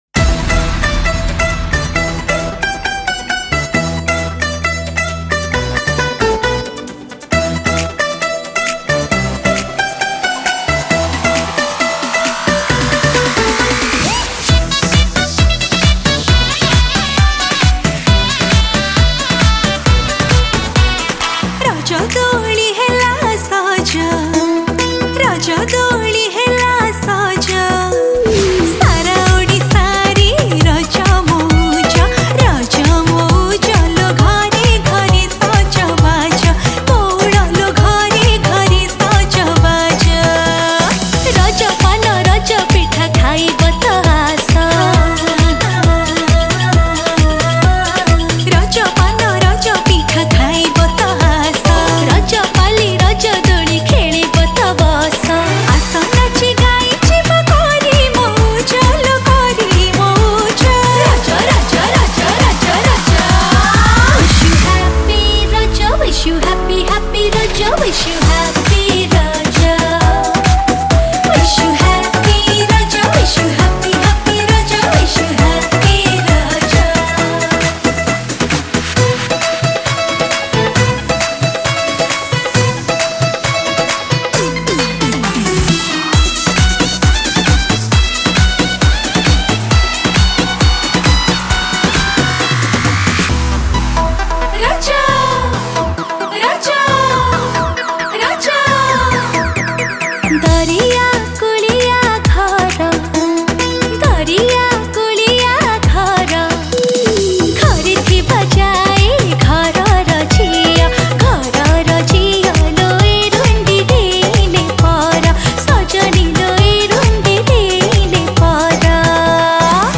Odia Raja Song
Keyboard Programming
Drums & Rhythm Programming